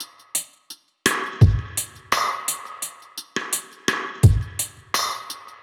Index of /musicradar/dub-drums-samples/85bpm
Db_DrumKitC_Wet_85-02.wav